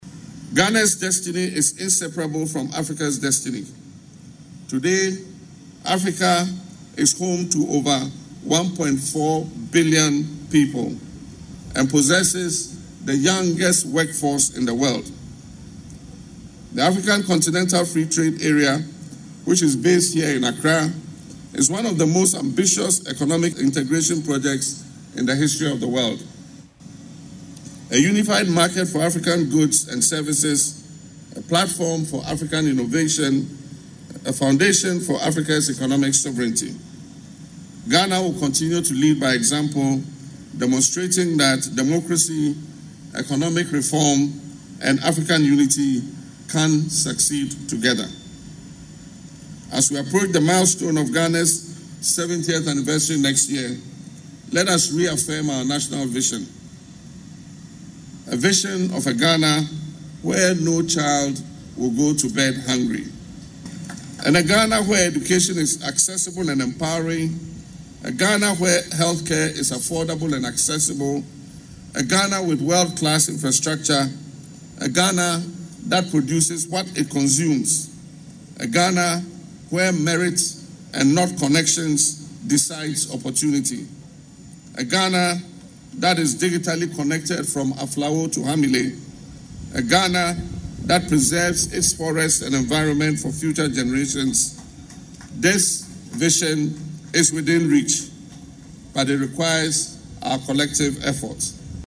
In his Independence Day speech, the President noted that Africa’s population of more than 1.4 billion people, combined with its vast natural resources and youthful workforce, places the continent in a strong position to drive global economic growth.